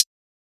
Closed Hats
HiHat 3.wav